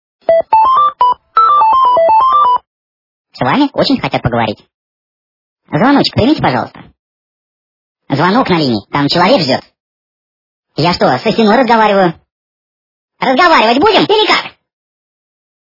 » Звуки » Смешные » Говорящий телефон - С вами очень хотят поговорить
При прослушивании Говорящий телефон - С вами очень хотят поговорить качество понижено и присутствуют гудки.